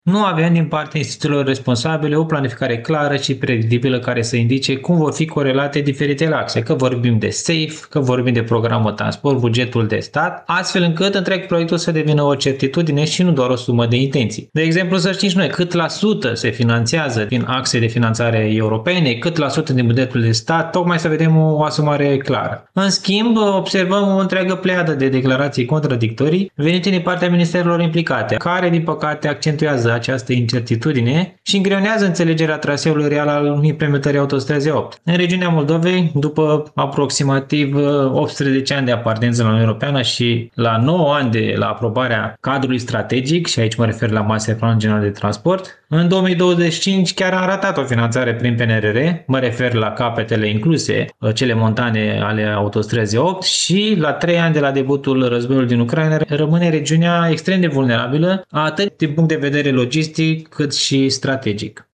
Președintele Asociației Moldova vrea Autostradă, Adrian Covăsnianu, fost secretar de stat în Ministerul Transporturilor, atrage atenția asupra lipsei unei viziuni coerente și transparente din partea autorităților.